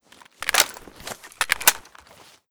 reload.ogg